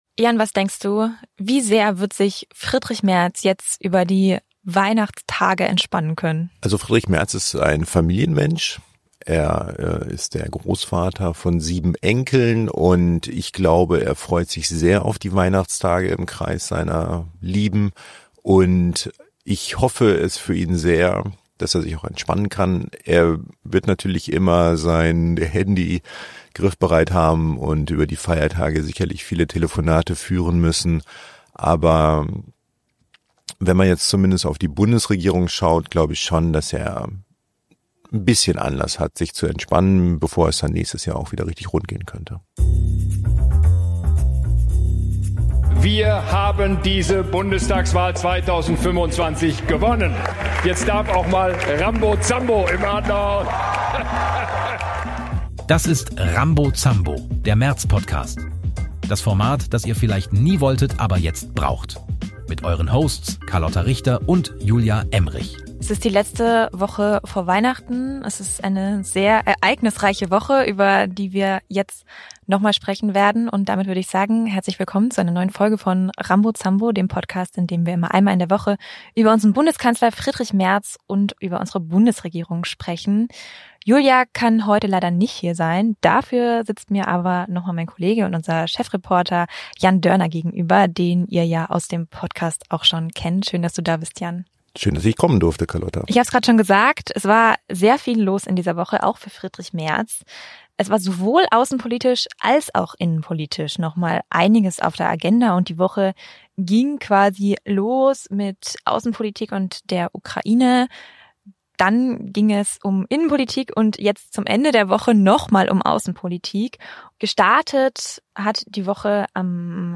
Zwei Frauen.